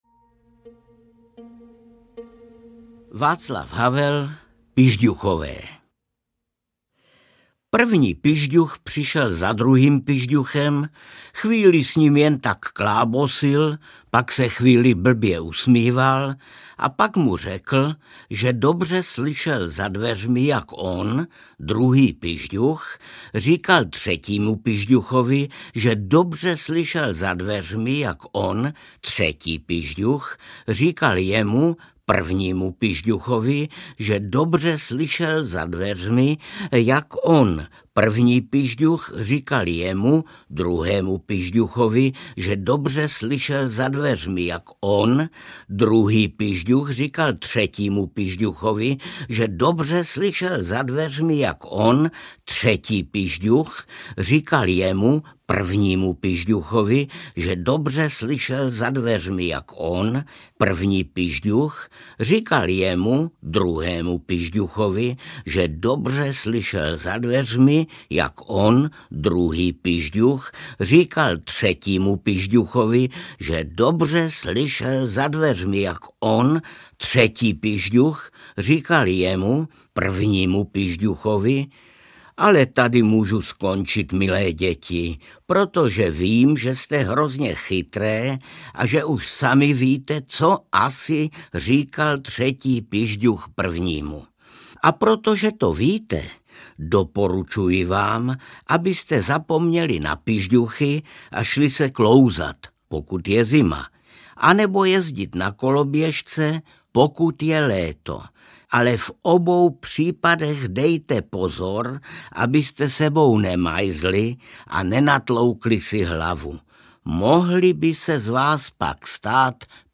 Pižďuchové audiokniha
Ukázka z knihy
• InterpretArnošt Goldflam